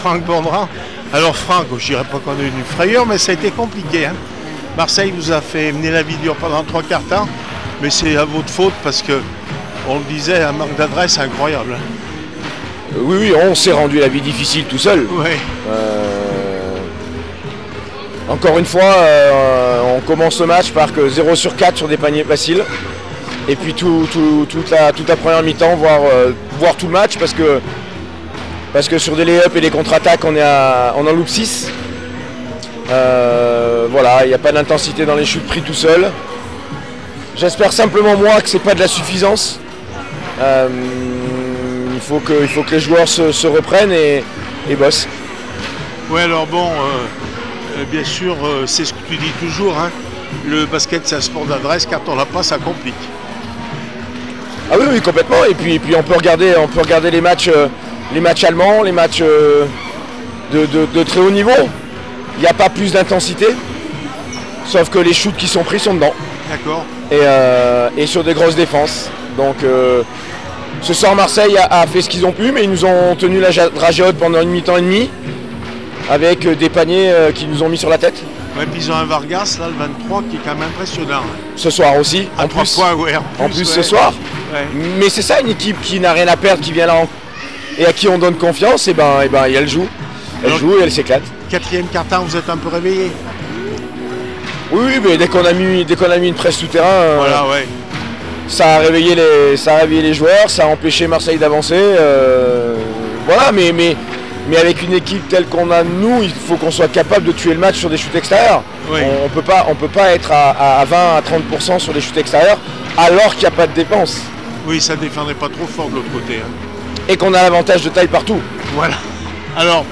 9 mars 2025   1 - Sport, 1 - Vos interviews
handi basket les aigles du Velay 70-53 Marseille réaction après match